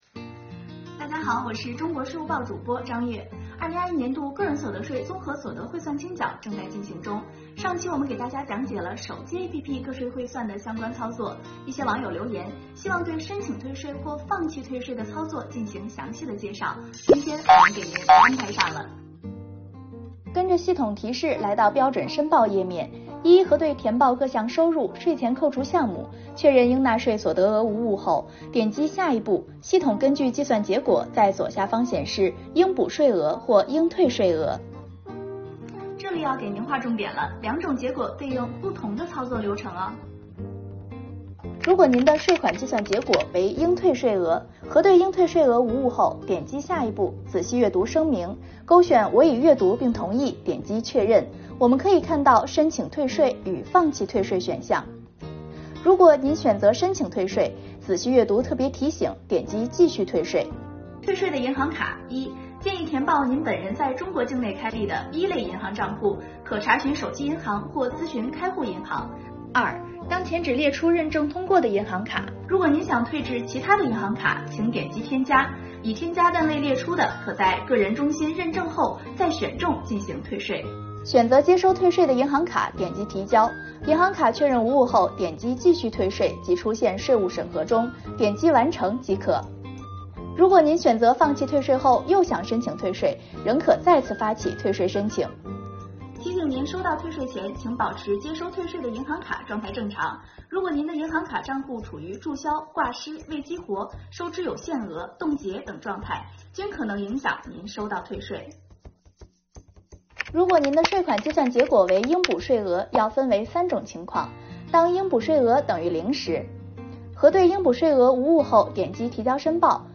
快跟着主播学习个税APP退税、补税操作详细攻略吧↑↑↑